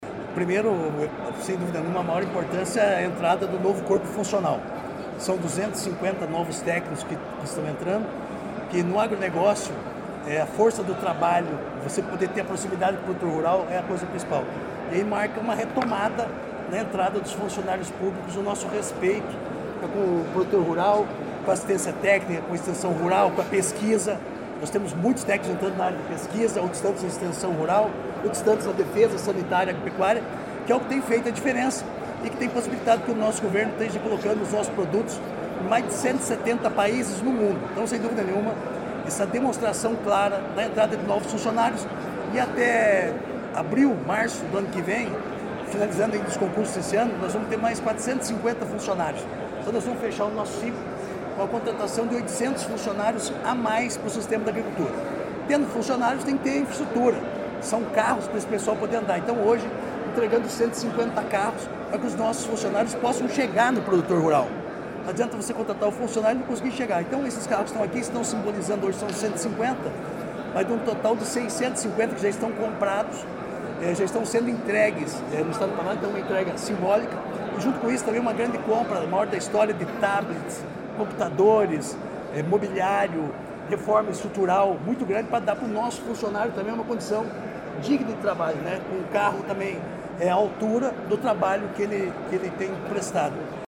Sonora do secretário da Agricultura e do Abastecimento, Márcio Nunes, sobre os 324 novos servidores e entrega de 250 veículos para a pasta